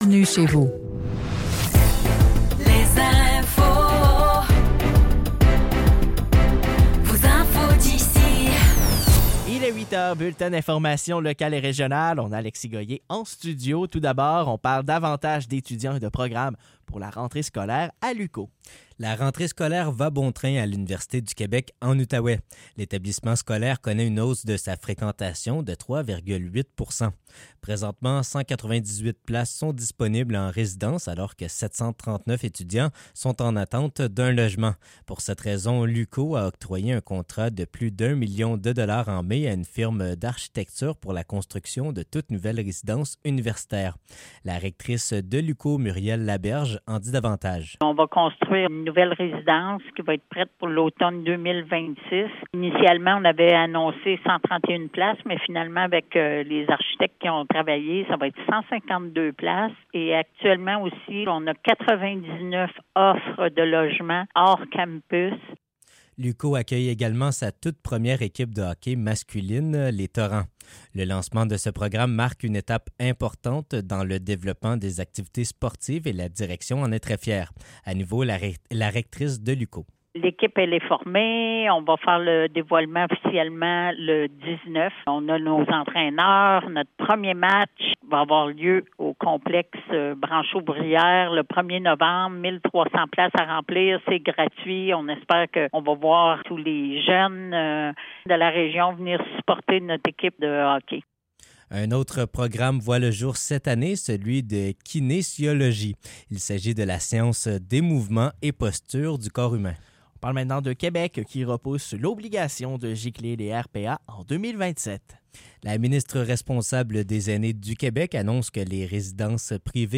Nouvelles locales - 4 septembre 2024 - 8 h